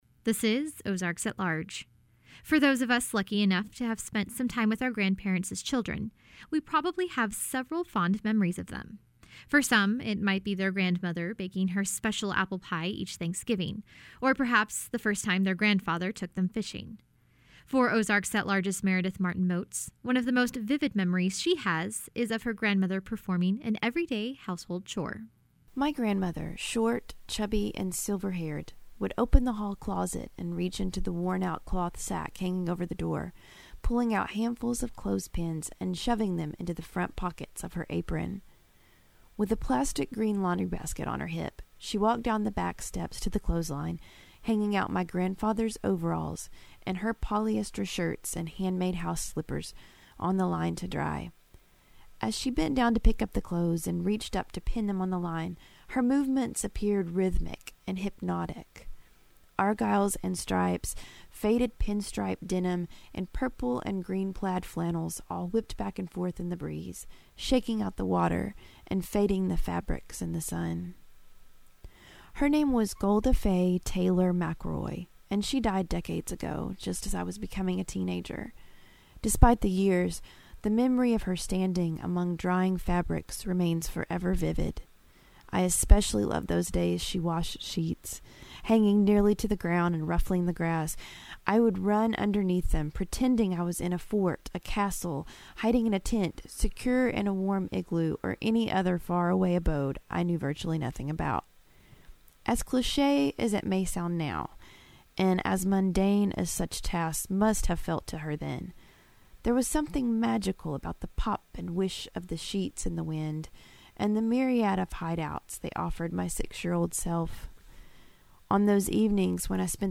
In this commentary